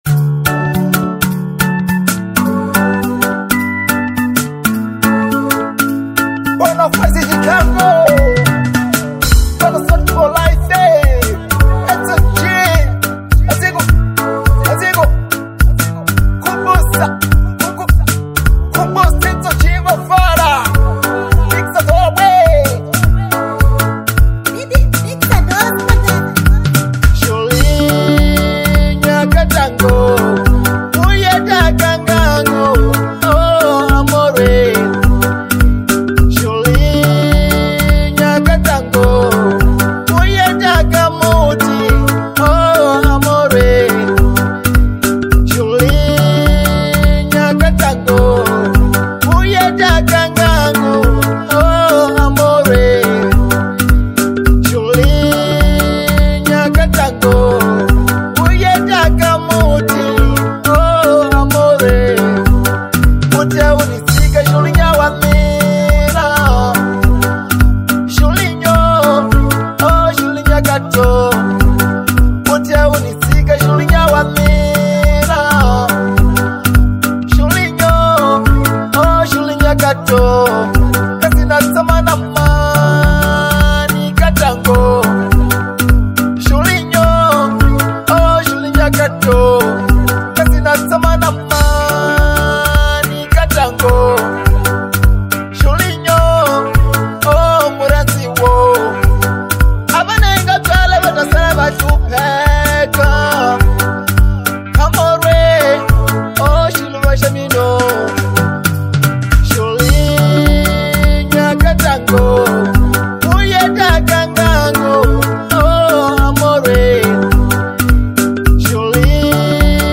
Genre : Marrabenta